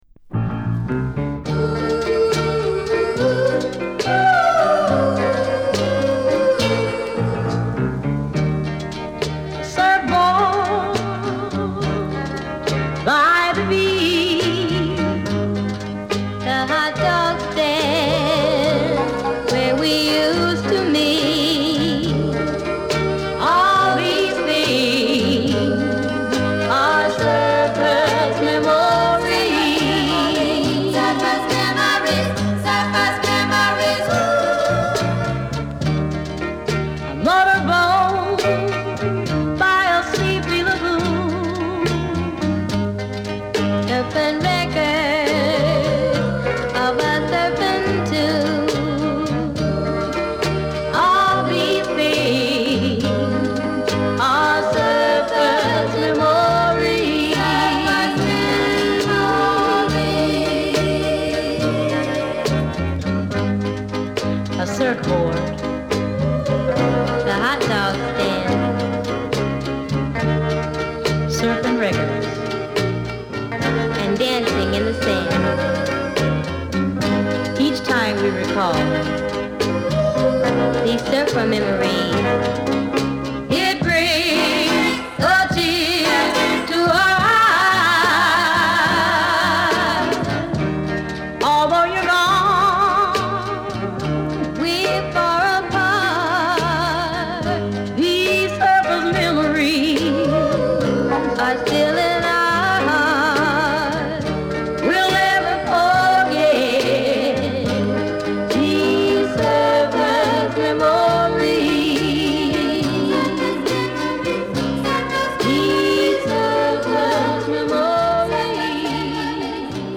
B面はコーラスのバッチリ効いたバラード・ナンバーを収録。